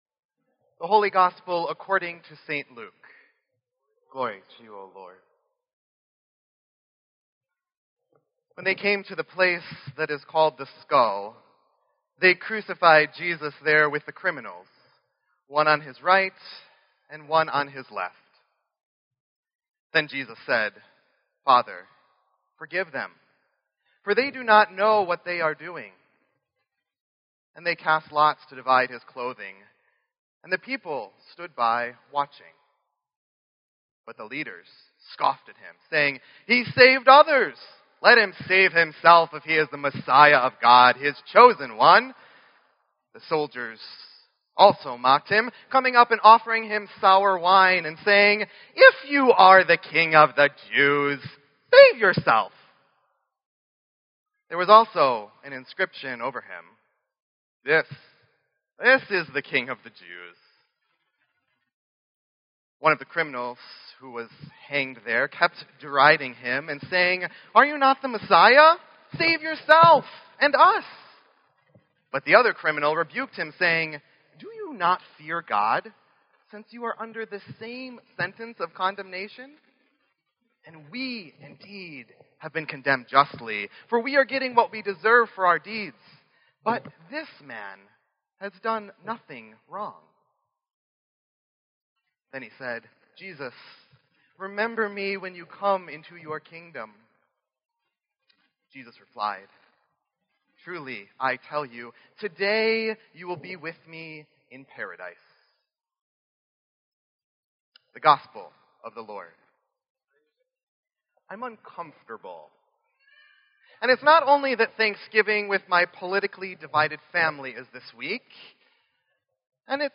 Sermon_11_20_16.mp3